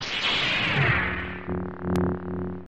light-saber_25535.mp3